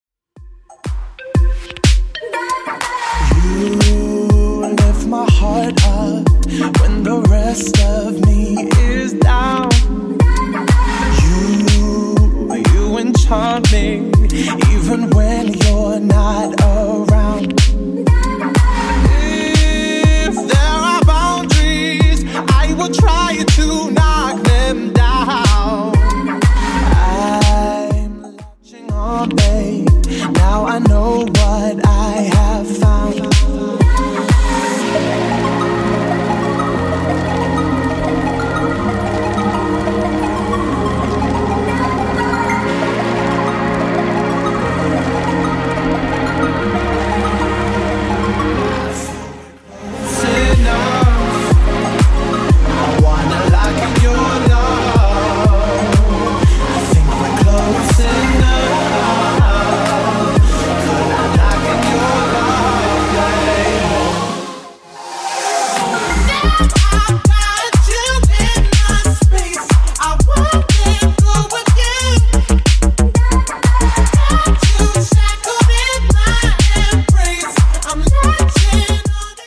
Genre: 80's Version: Clean BPM: 110